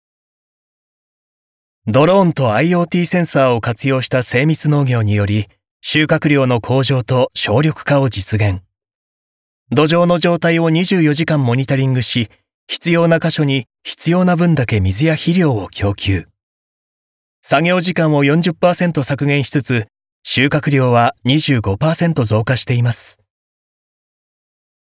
ボイスサンプル
• ナレーション：爽やか、信頼感、説明等